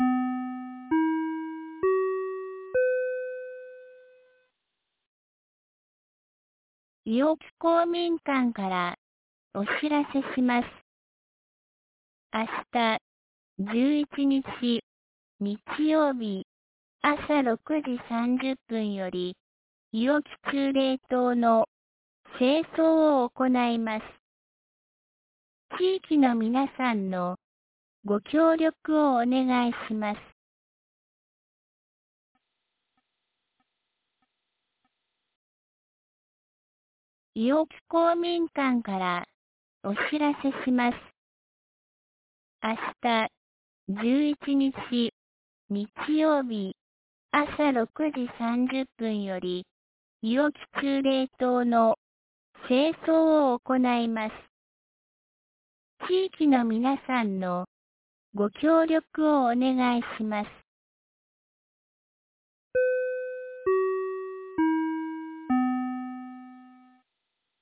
2024年08月10日 17時21分に、安芸市より下山、伊尾木へ放送がありました。